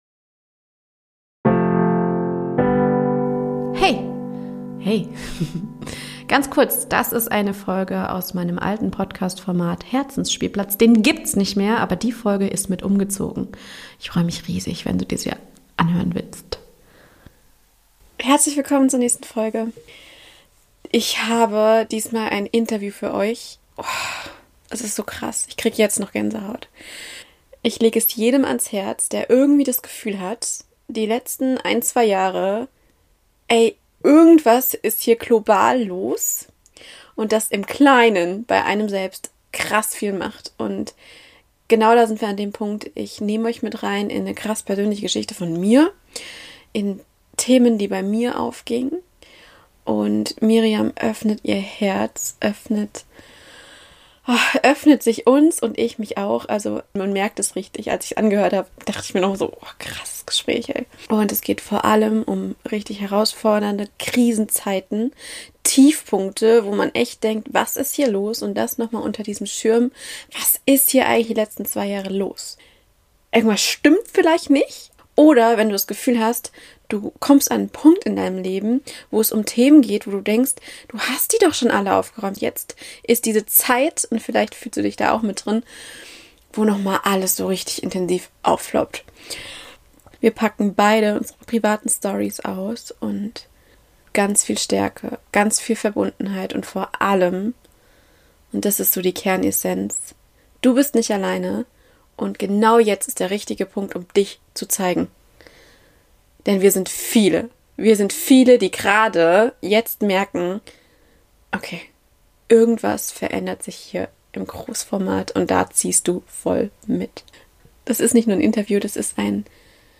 Zwei Frauen, die sich schonungslos offen zeigen, voller Erfahrung, Feingefühl und Stärke und dabei mal eben einen Raum für Verbindungspunkte schaffen.